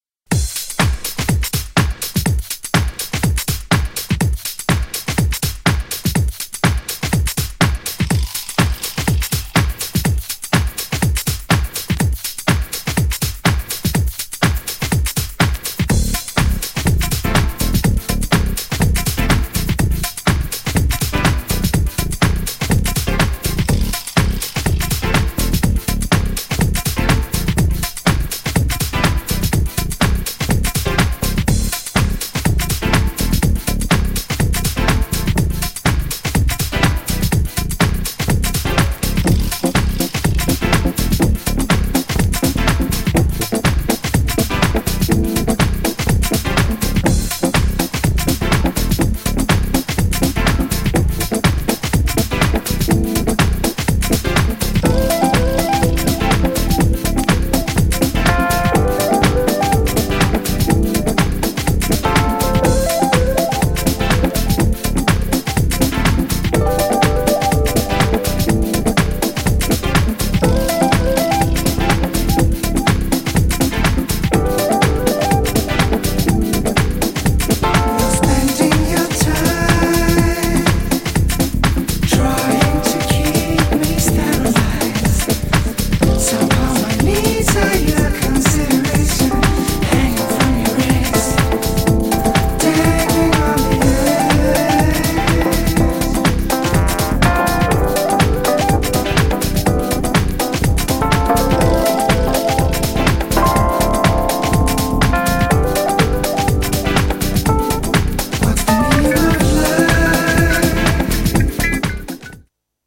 アイスランド発のエレクトロニックなバンド。
GENRE House
BPM 121〜125BPM